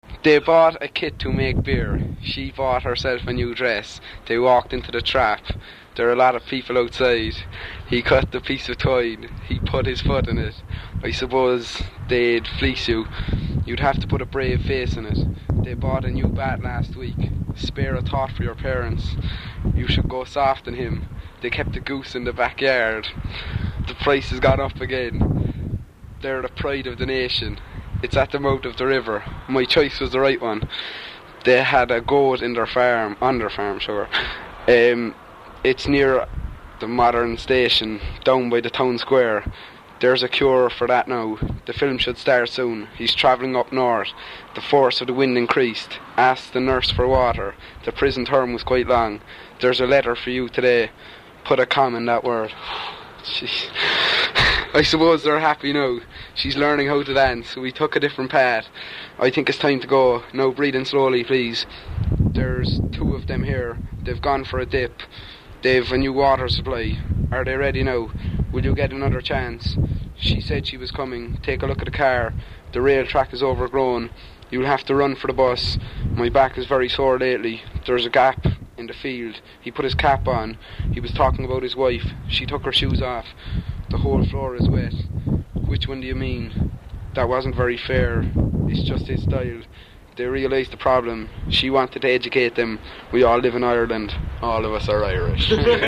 Sample sentences with local Carlow speaker
Carlow_M_20_(sample_sentences).mp3